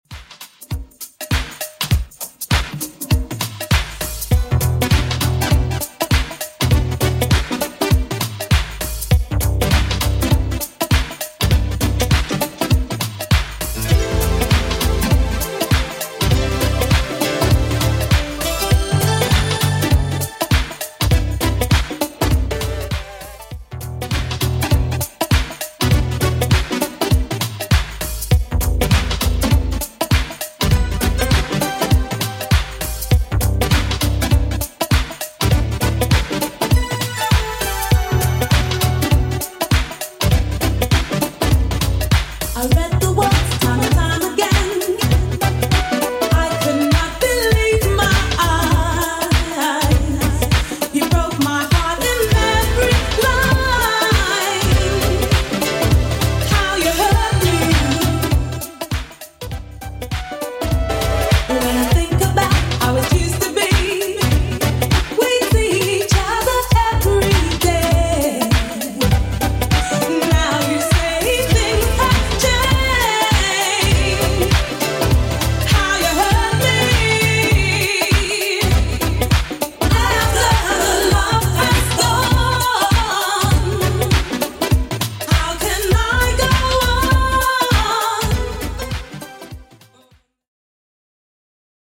Genre: 70's
BPM: 122